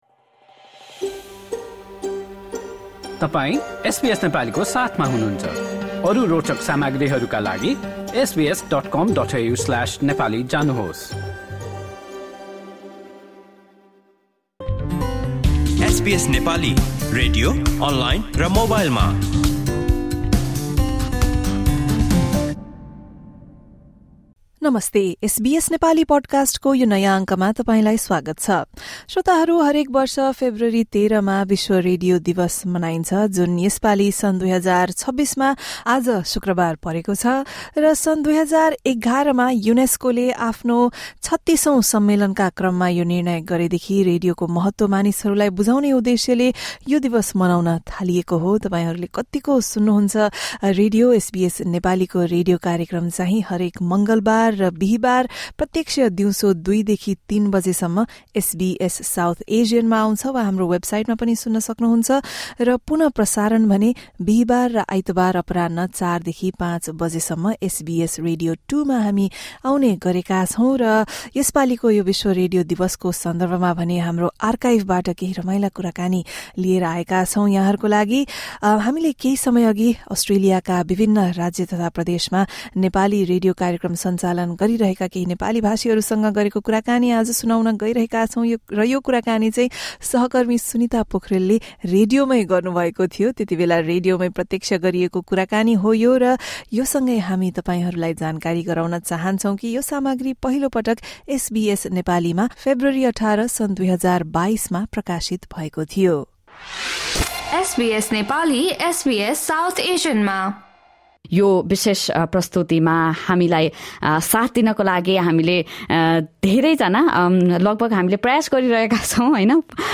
यही सन्दर्भमा हामीले केही समय अगि अस्ट्रेलियाका विभिन्न राज्य तथा प्रदेशमा नेपाली रेडियो कार्यक्रम सञ्चालन गरेका केही नेपालीभाषीहरूसँग गरेको कुराकानी सुन्नुहोस्।